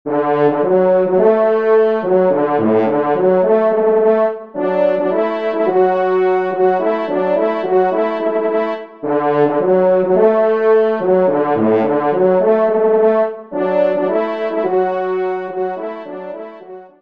Genre :  Divertissement pour Trompes ou Cors
3e Trompe